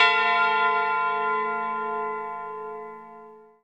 SouthSide Tubular Bell (5).wav